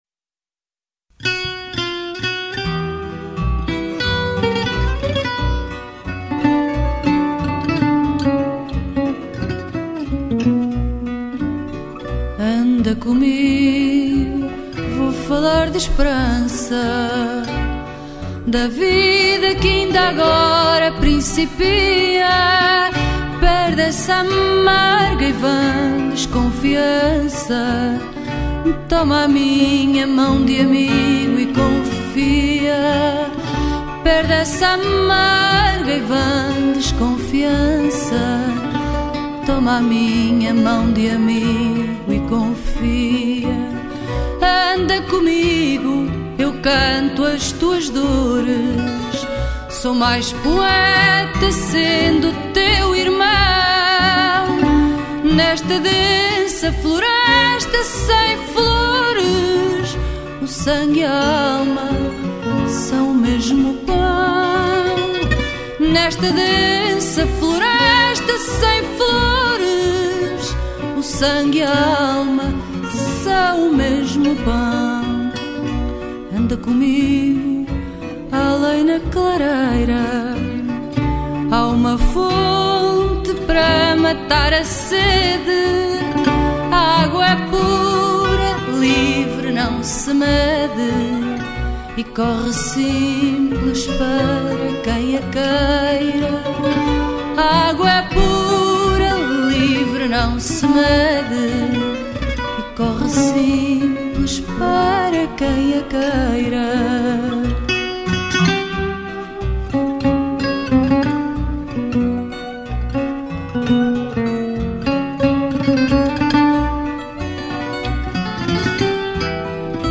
Genre: Fado, Folk